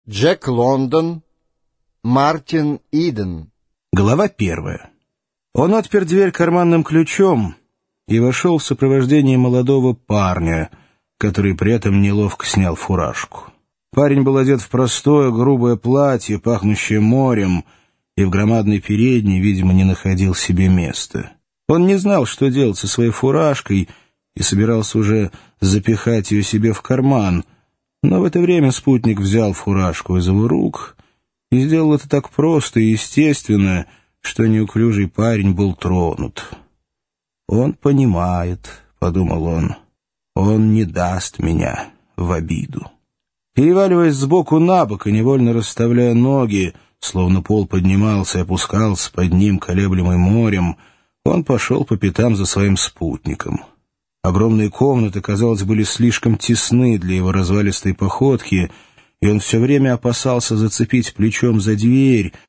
Аудиокнига Мартин Иден | Библиотека аудиокниг